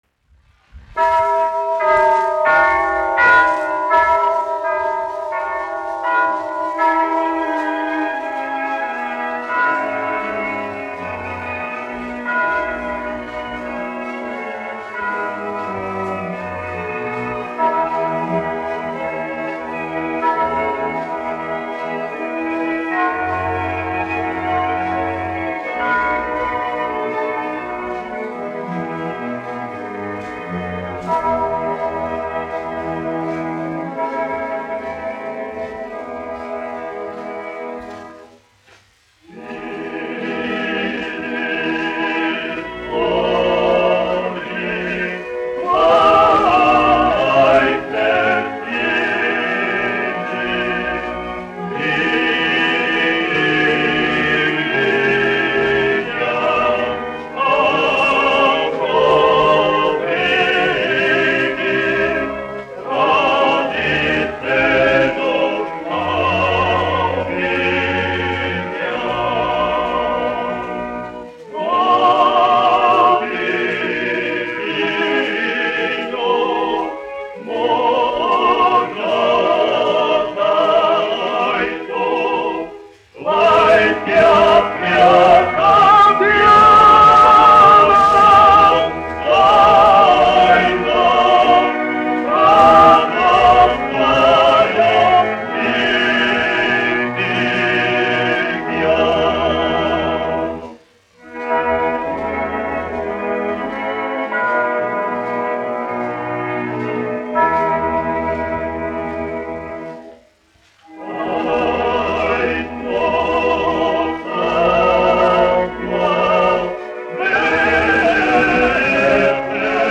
1 skpl. : analogs, 78 apgr/min, mono ; 25 cm
Ziemassvētku mūzika
Kori (vīru) ar orķestri
Skaņuplate